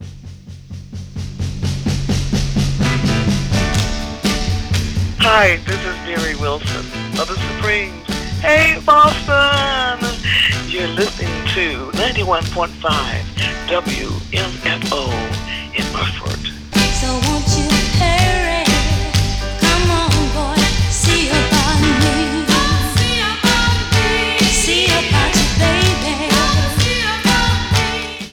MaryWilson_Supremes_WmfoStationID_Mix5.wav